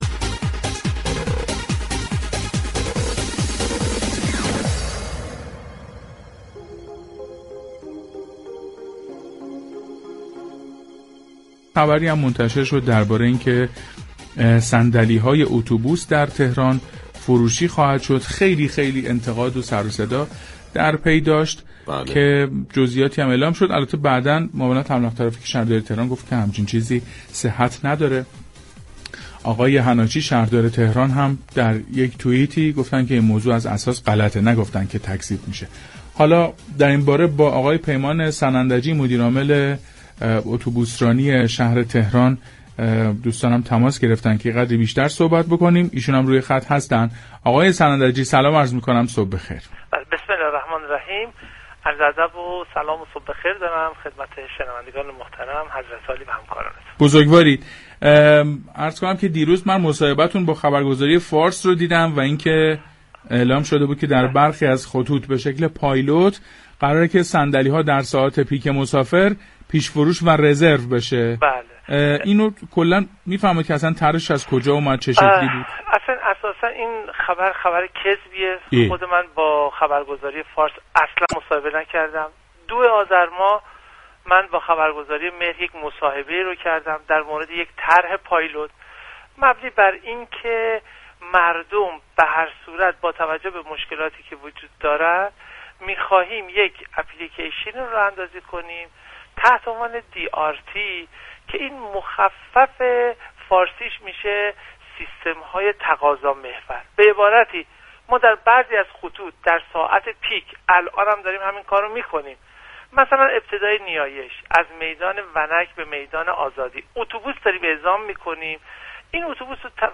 در گفتگو با پارك شهر